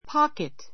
pocket 中 A1 pɑ́kit パ ケ ト ｜ pɔ́kit ポ ケ ト 名詞 （洋服・カバンなどの） ポケット Don't put your hands in your pockets.